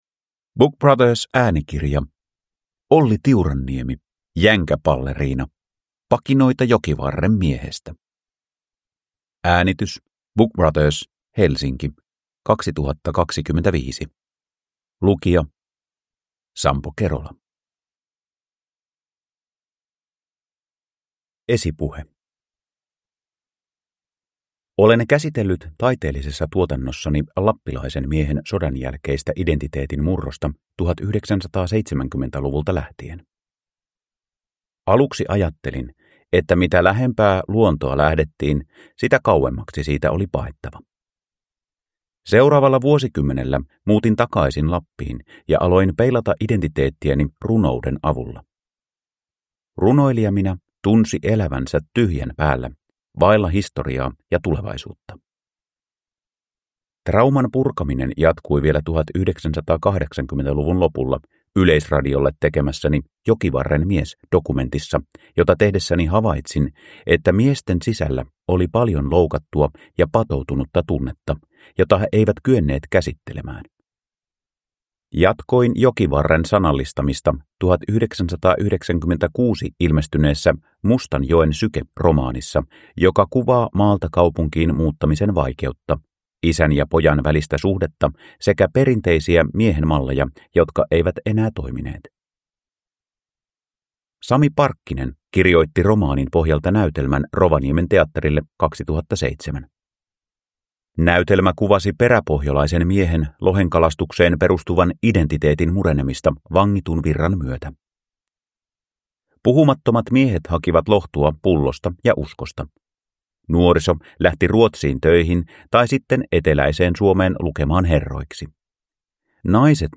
Jänkäpalleriina – Ljudbok